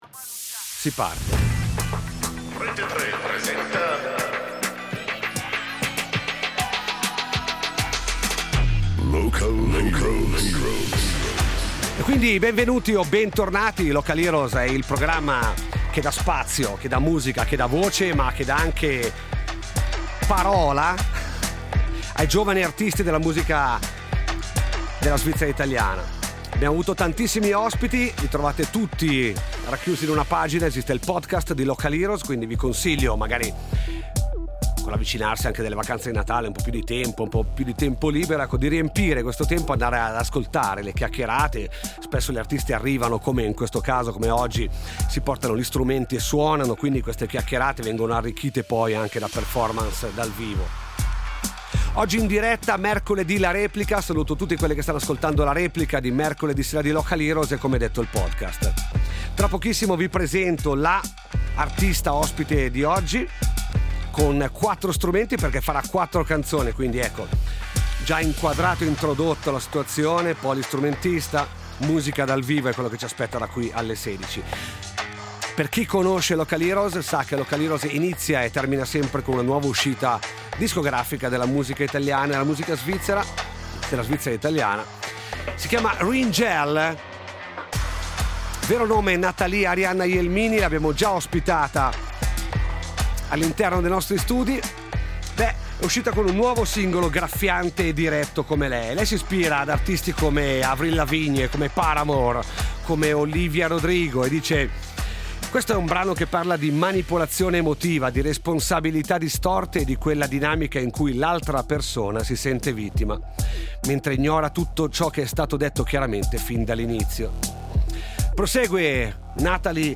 cantautrice ticinese che unisce sonorità elettroniche e folk in atmosfere intime e sperimentali.